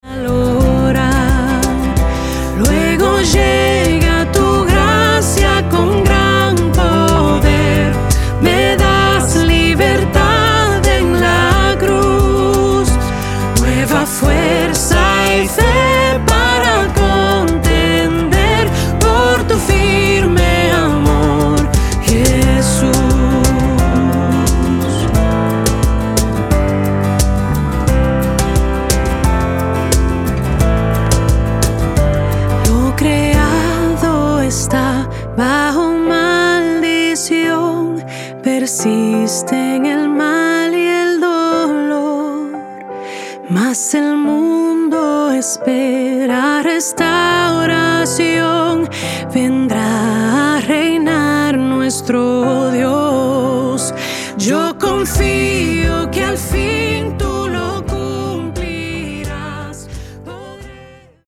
Acordes - C